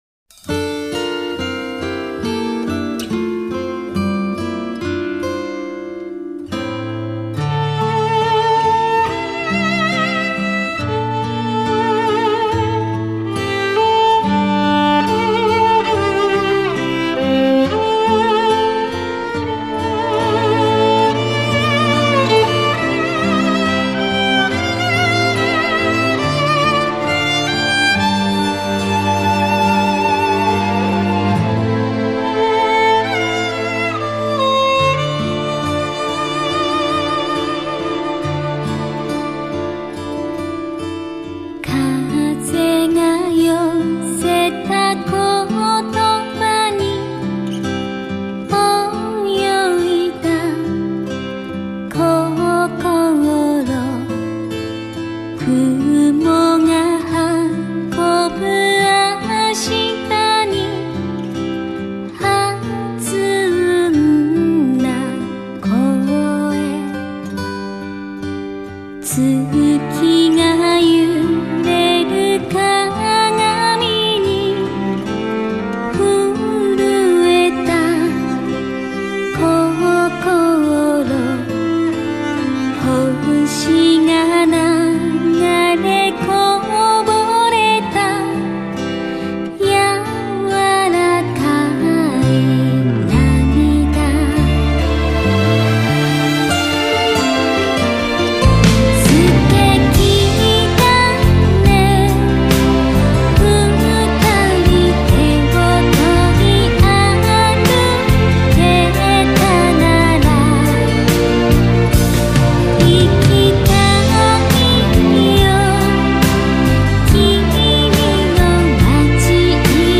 主题曲MP3